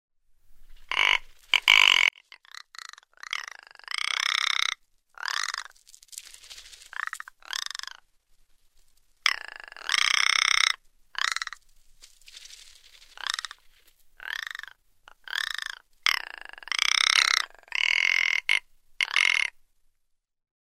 Kiiruna